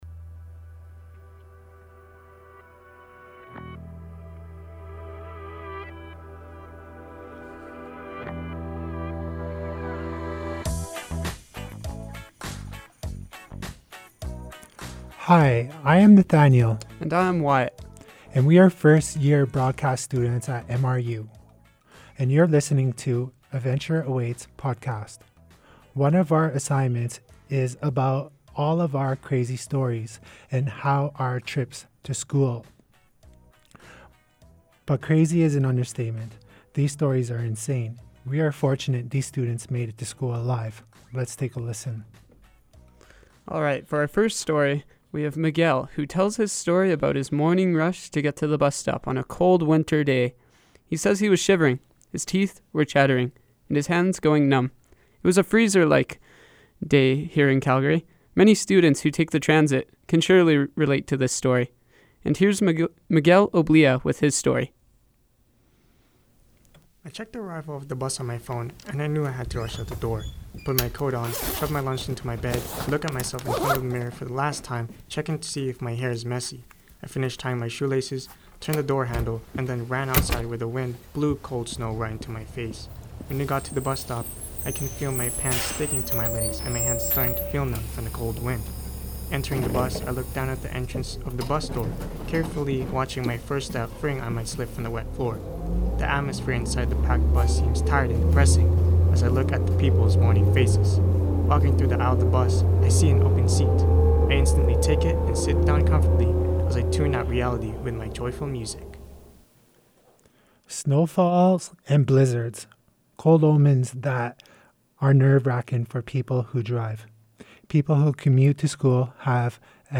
This podcast is called Adventure Awaits.  It features students sharing stories about their commutes to school.